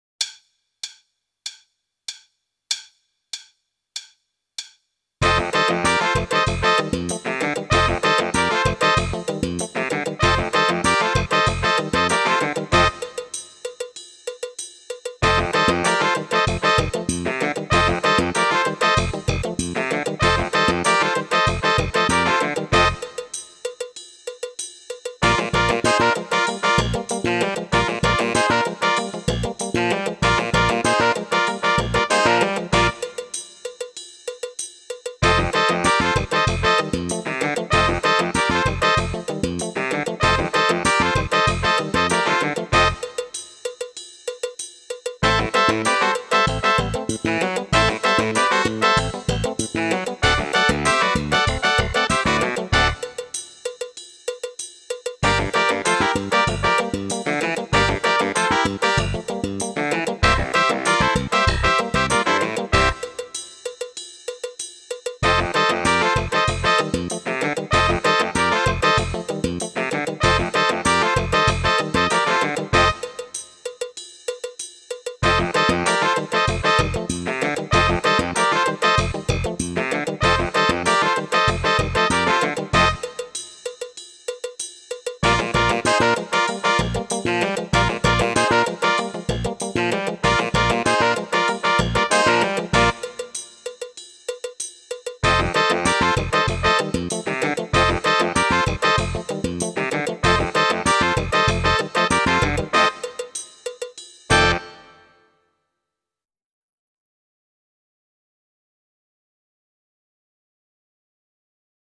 Meespeel CD
23. Twee korte solo's Funk